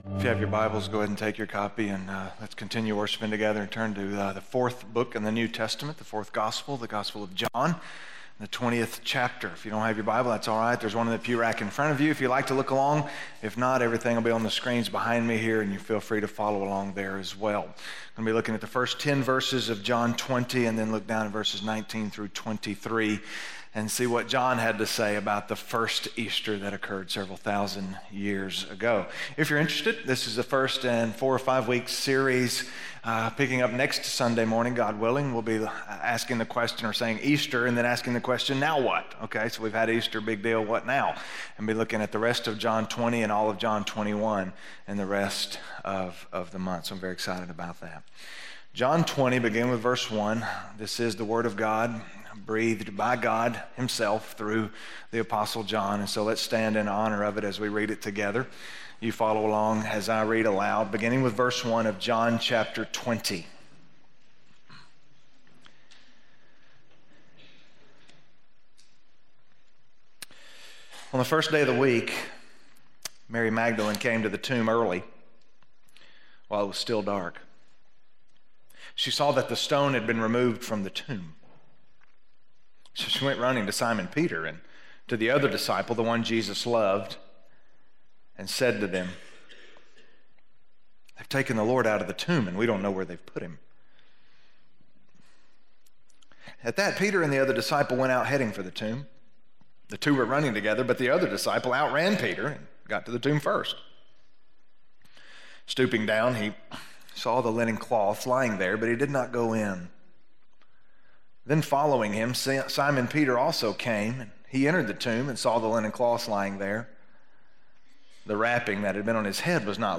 Peace Be with You - Sermon - West Franklin